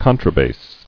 [con·tra·bass]